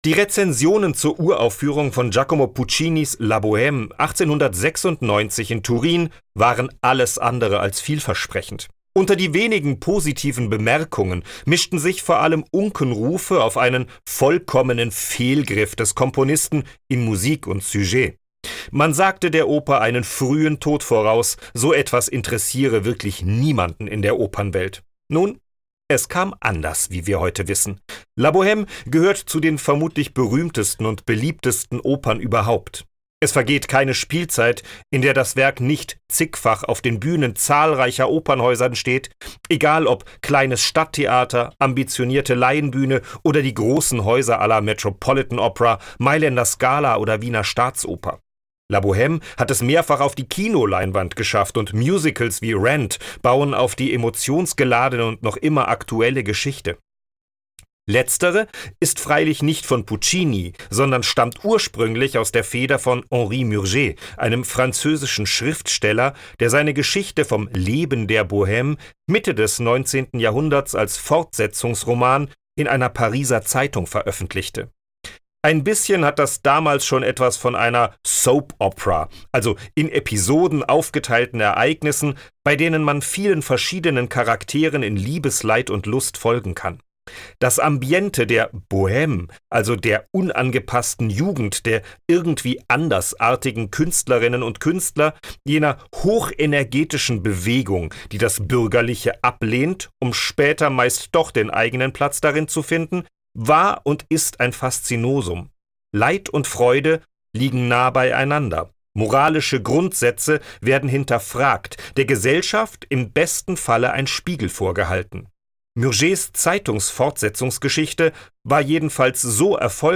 Höreinführung